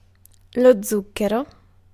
Ääntäminen
France (Paris): IPA: /sykʁ/